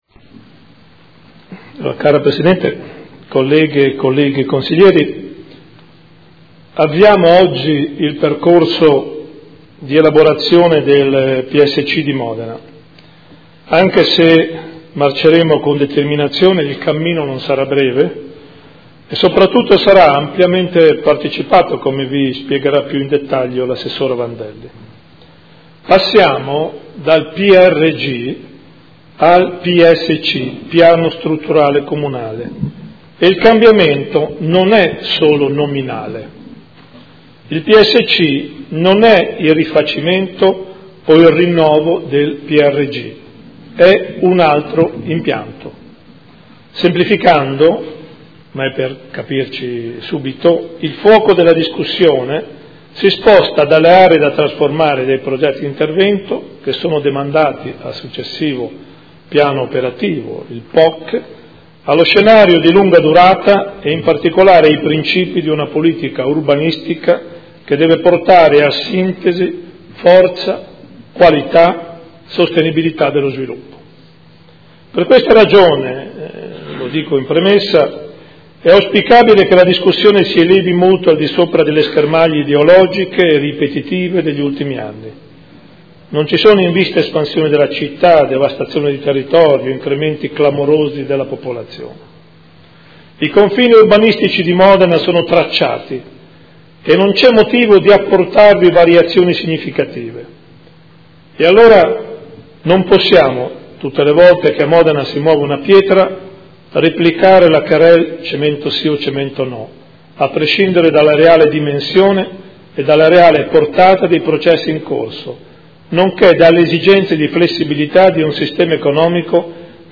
Seduta del 27/07/2015. Comunicazione su percorso verso il nuovo PSC
Sindaco